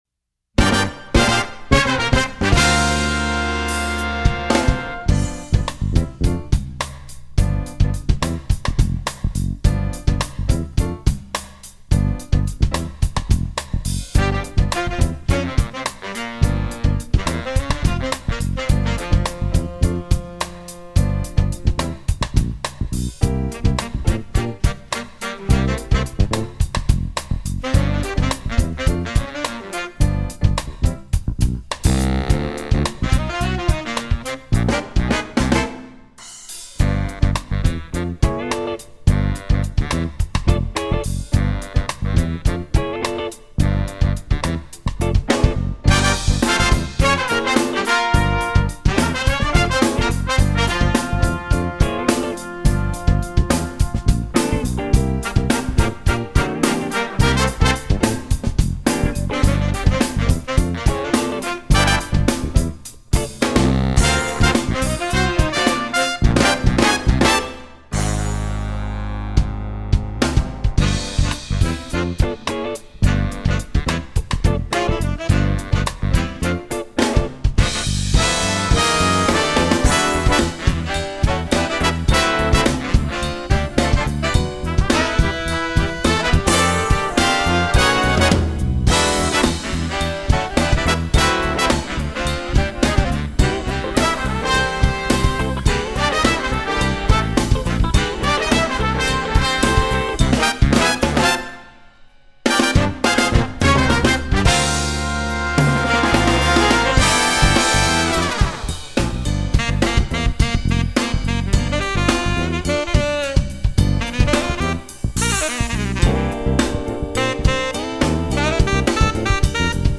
Funk
Jazz Band